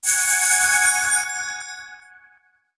BT_Chest_Open.wav